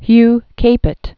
(hy kāpĭt, kăpĭt, kă-pā)